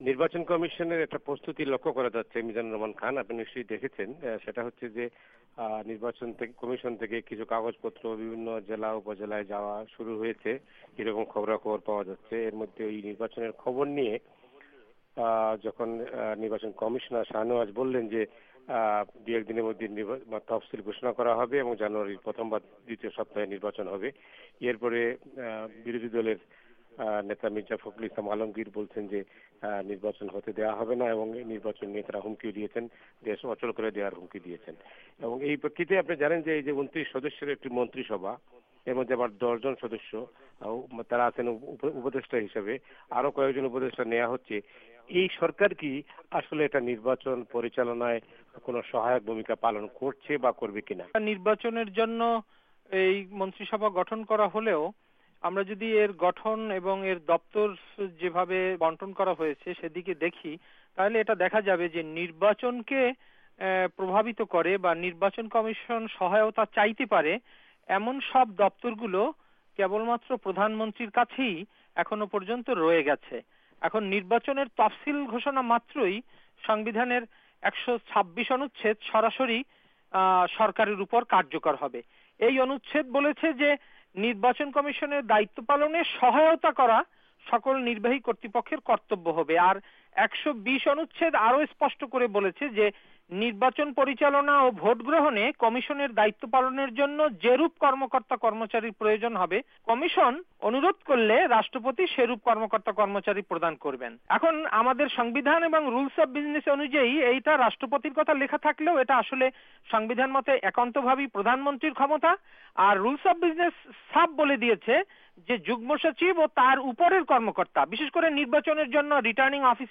কথা বলছেন এখন আন্তর্জাতিক টেলিকনফারেন্স লাইনে